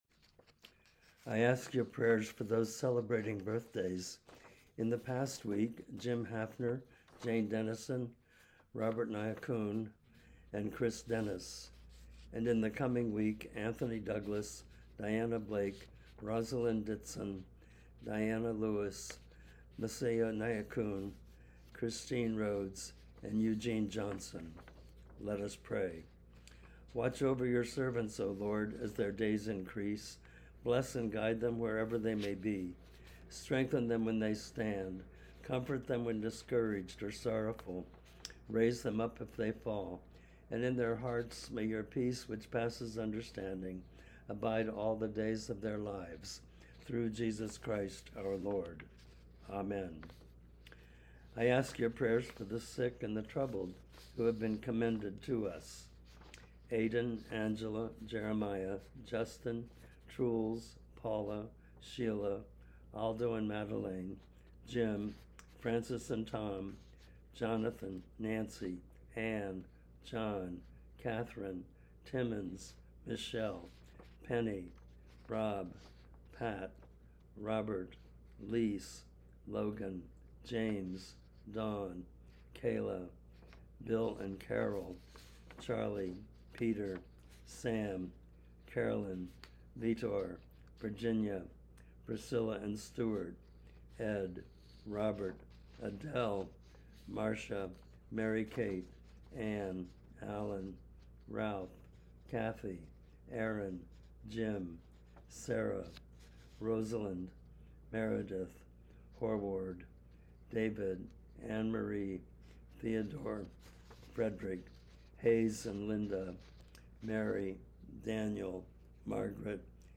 Recording of Weekly Prayers: